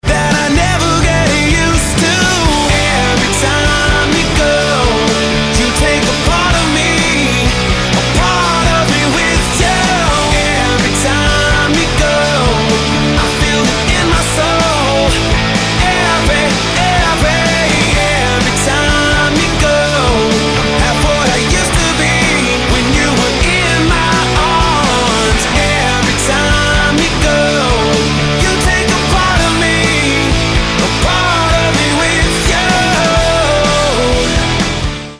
Americká rocková skupina.